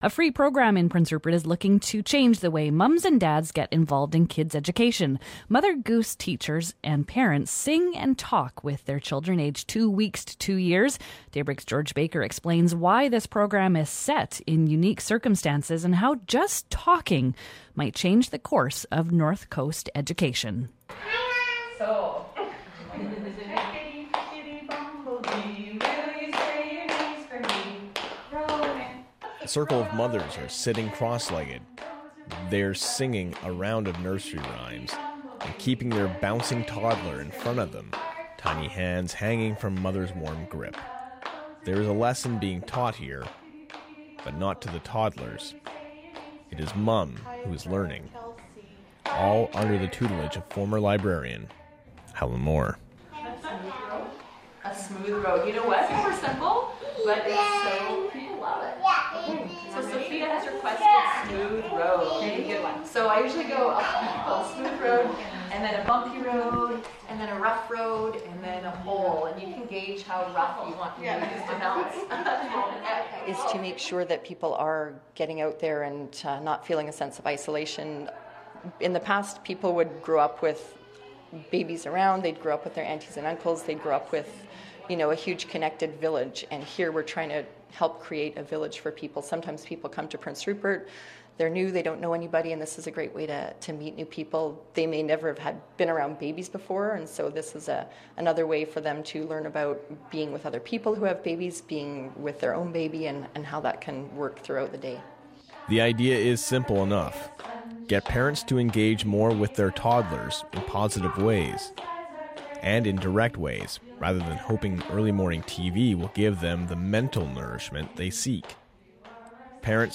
Prince Rupert parents sing to their toddlers, and learn something new in the proces.
They're doing this by singing to them, and the parents are learning something, too.